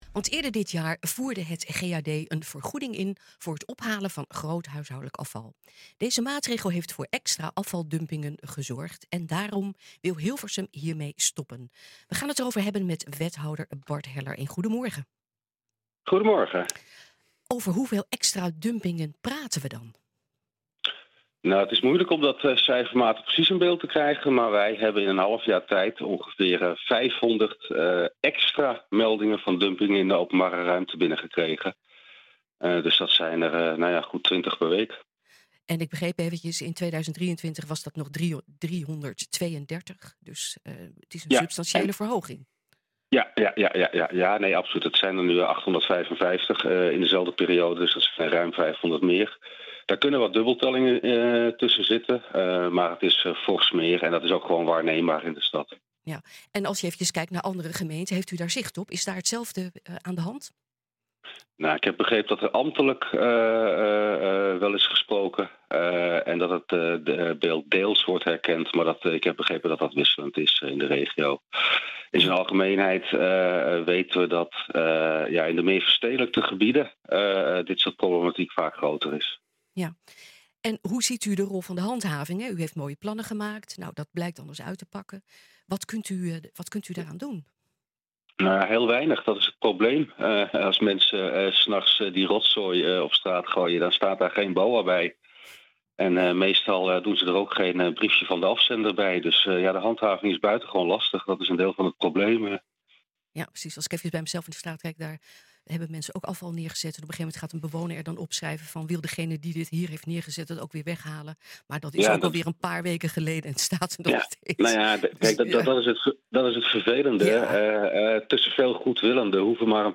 Deze maatregel heeft voor extra afvaldumpingen gezorgd en daarom wil Hilversum hiermee stoppen. We gaan het erover hebben met wethouder Bart Heller.